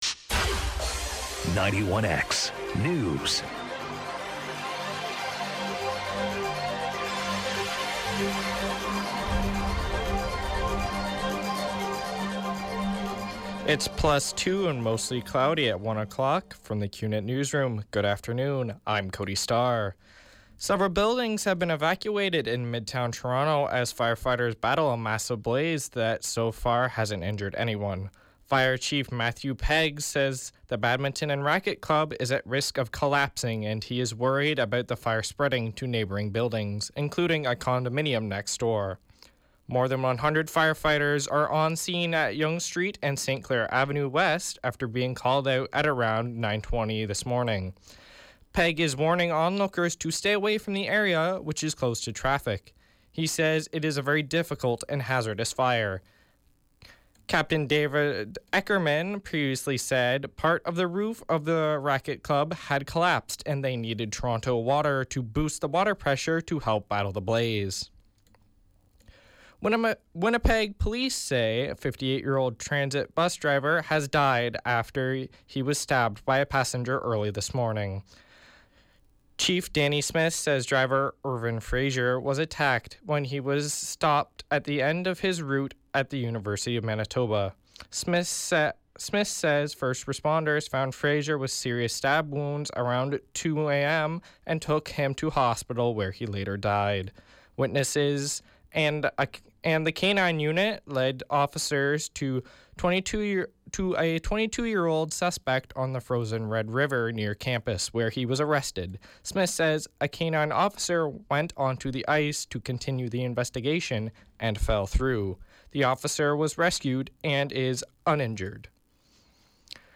91X Newscast – Tuesday, Feb. 14, 2017, 1 p.m.